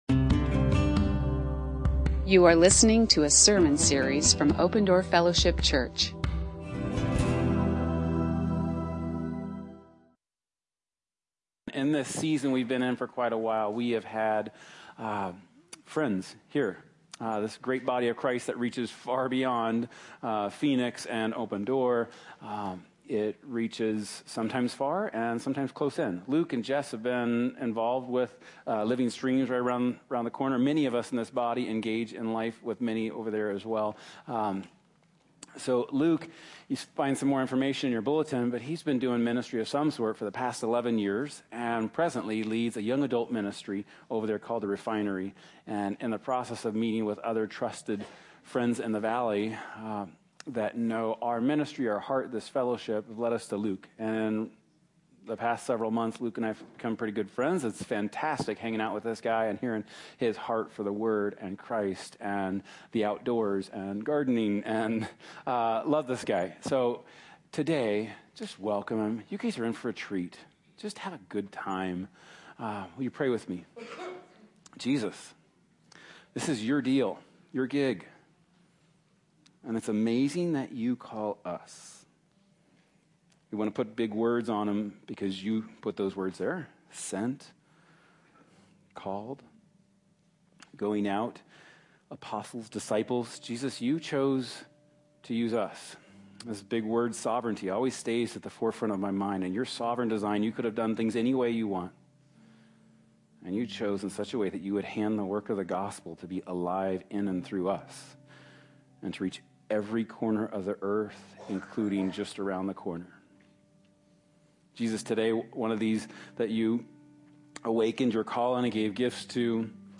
Guest Speakers ~ Open Door Fellowship Church Phoenix, AZ Podcast
You are listening to an audio recording of Open Door Fellowship Church in Phoenix, Arizona.